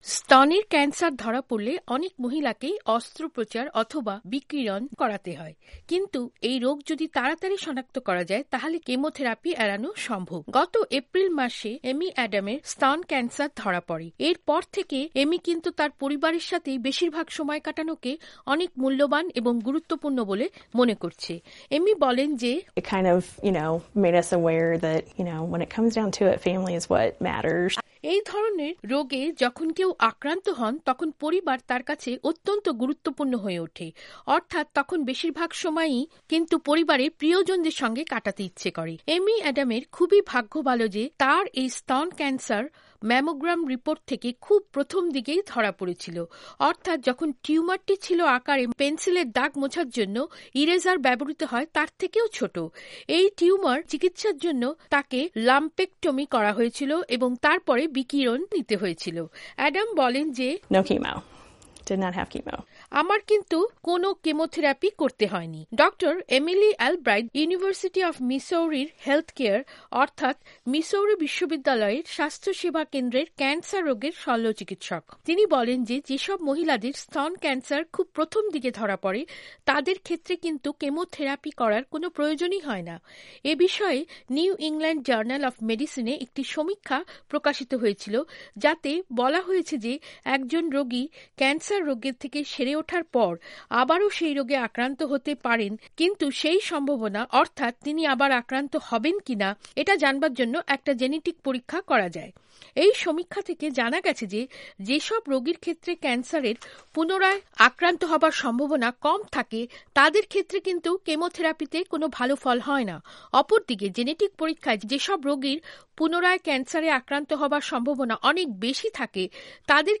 বিজ্ঞান ও প্রযুক্তি পর্বে প্রতিবেদনটি পড়ে শোনাচ্ছেন